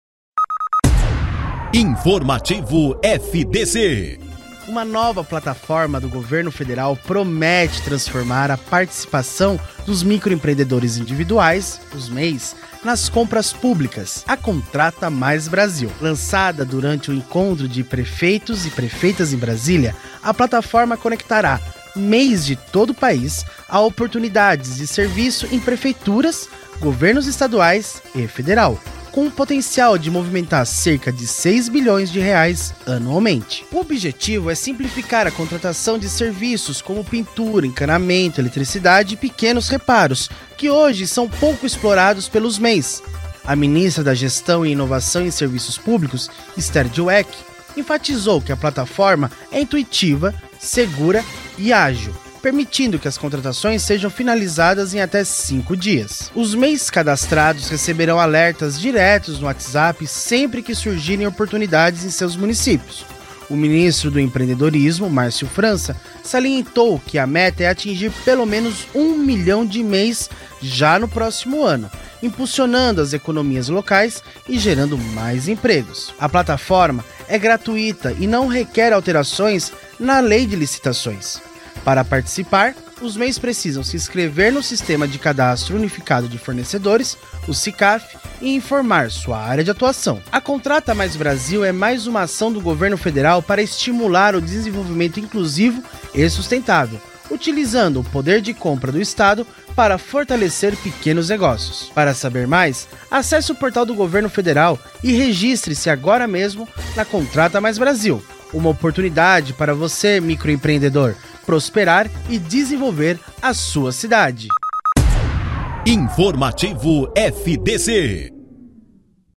Ouça a notícia: Governo Federal Lança Plataforma Inovadora para Impulsionar Participação de MEIs em Compras Públicas
INFORMATIVO-FDC-MEI-CONTRATA-MAIS-BRASIL.mp3